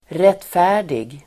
Uttal: [²r'et:fä:r_dig]